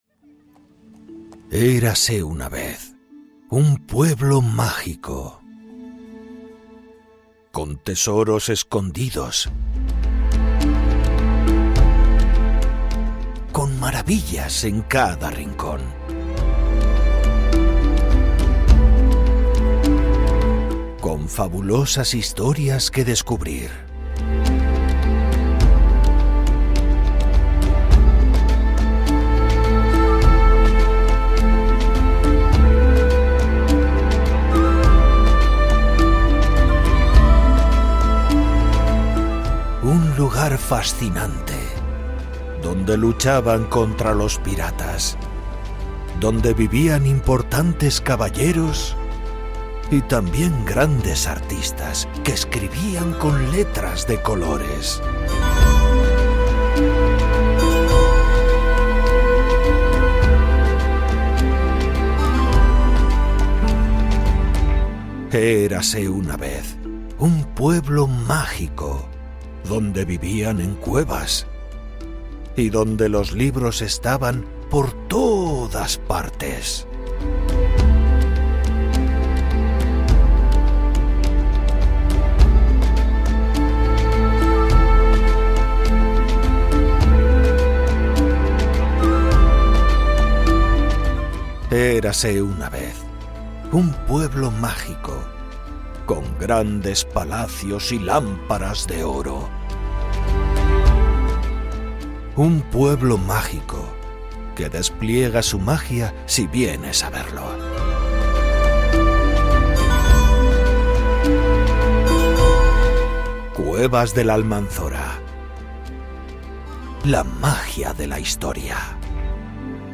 Professional quality recordings, quick delivery times, and remote connection option so that you can direct the session as you wish.
This is how my voice sounds
VOICEOVER for FITUR 2024 Cuevas del Almanzora - ``La magia de la historia``